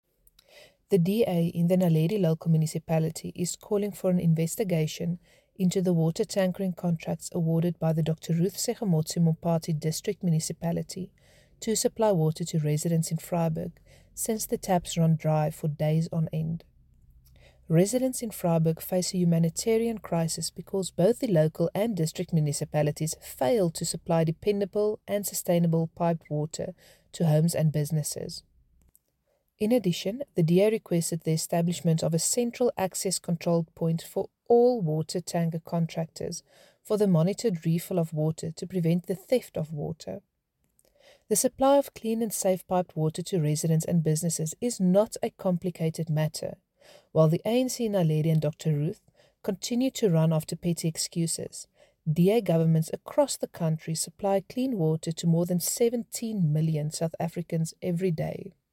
Issued by Cllr Hendriëtte van Huyssteen – DA Caucus Leader: Naledi Local Municipality
Note to Broadcasters: Find linked soundbites in